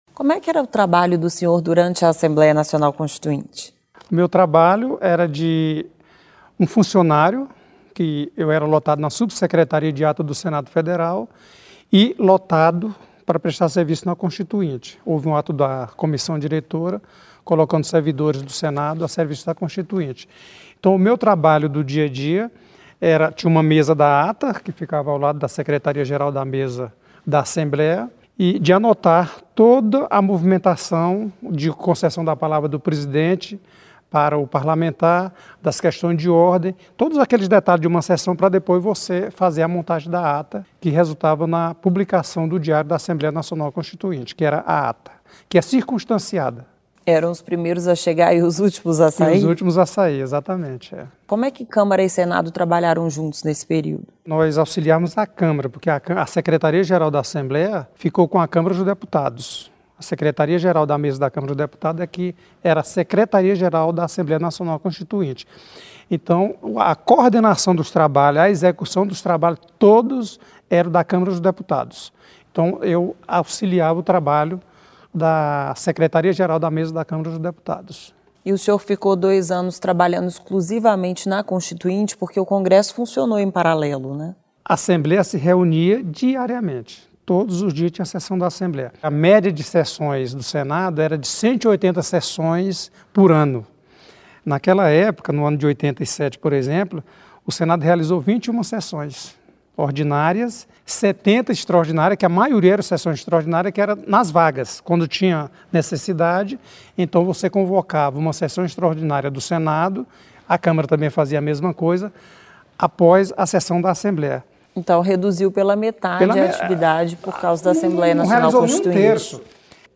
Nesta entrevista, o ministro do Tribunal de Contas da União Raimundo Carreiro fala sobre as atividades do Senado Federal durante a elaboração da Constituição.